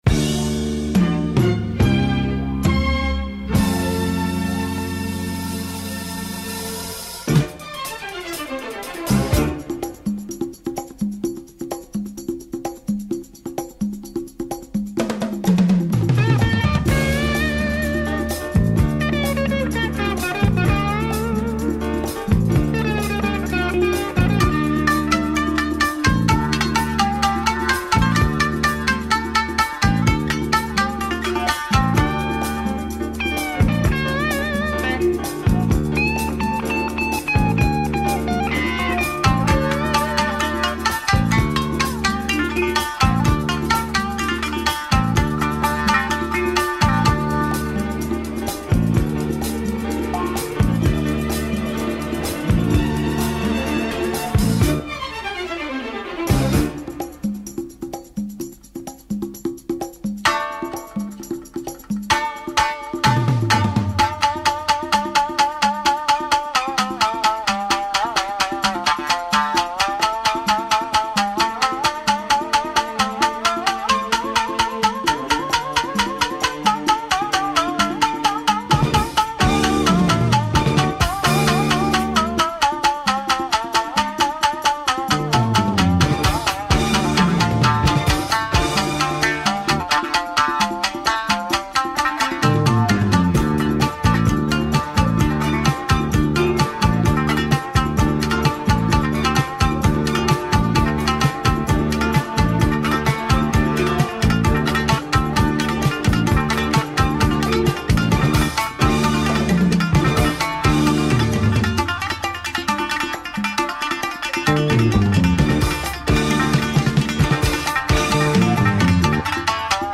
Min'yo groove obscurity with heavy breaks !